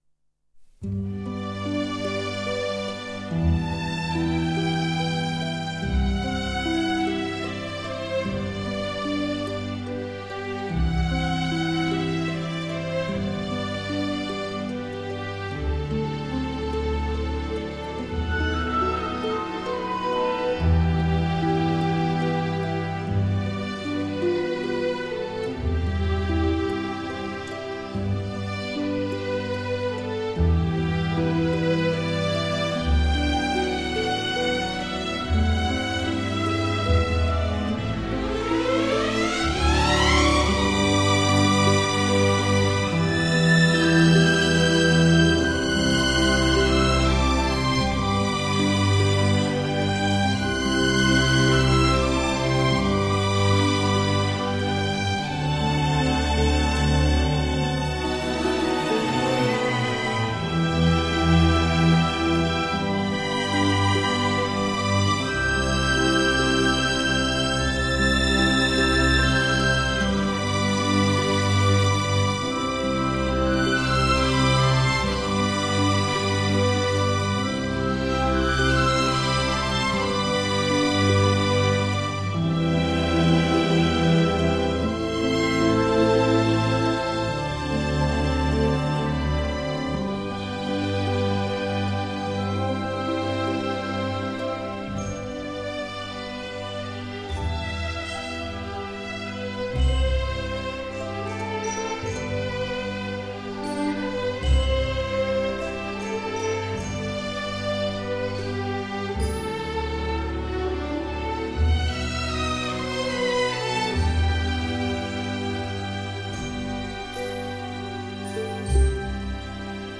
以管弦乐演奏
诗意的选辑，精湛的录音，典雅的编曲，浪漫的演奏， 聆听本专辑令人感到时光倒流，勾起无限回忆。